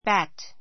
bat 1 小 A1 bǽt バ ト 名詞 （野球などの） バット , （卓球 たっきゅう の） ラケット 関連語 racket はテニスやバドミントンのラケットのようにネットが張ってあるもの. hit a ball with a bat hit a ball with a bat バットでボールを打つ at bat at bat at bat （野球で） 打席について Our side is at bat.